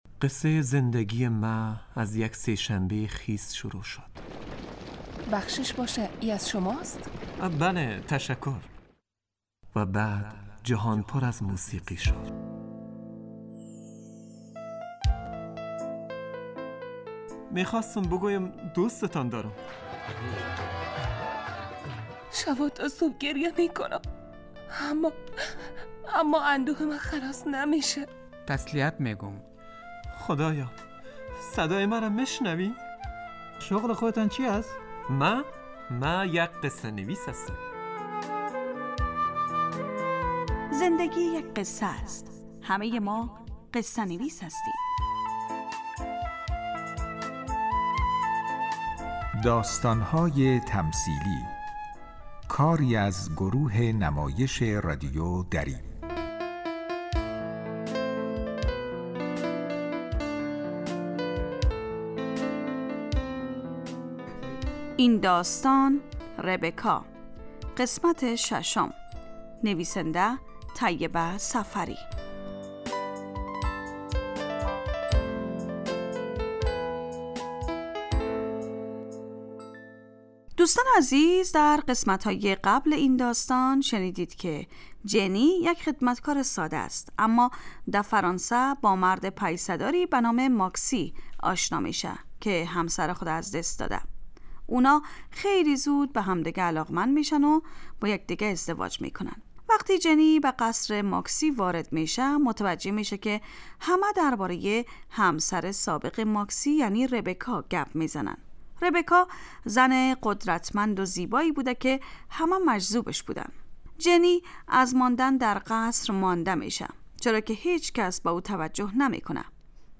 داستانهای تمثیلی نمایش 15 دقیقه ای هستند که هر روز ساعت 4:45 عصربه وقت وافغانستان پخش می شود.